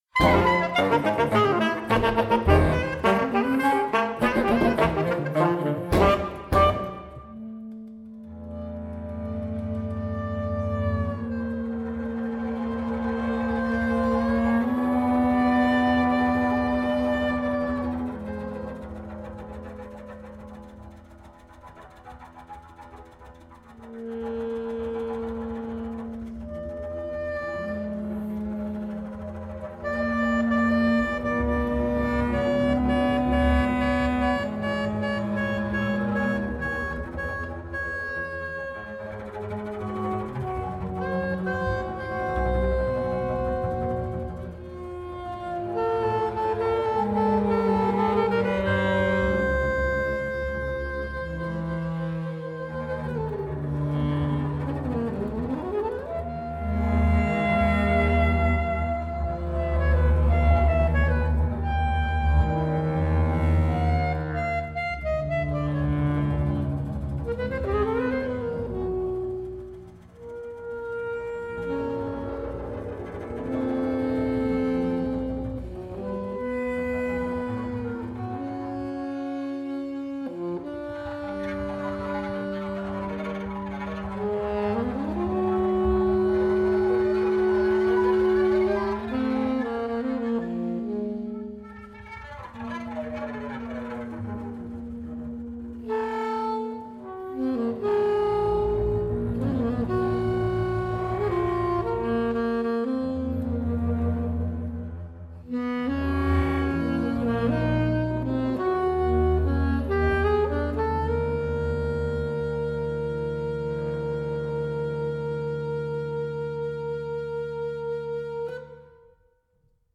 soprano, alto & tenor saxophones
double bass
bass saxophone